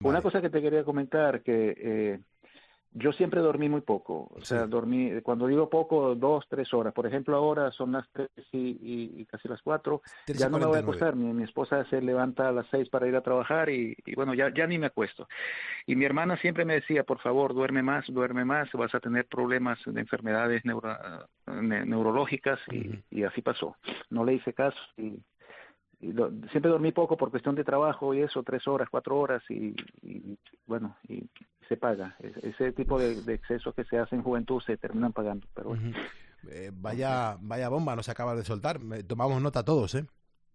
La advertencia de un enfermo de Parkinson de Gijón a la hora de ir a dormir: "Terminó pasando"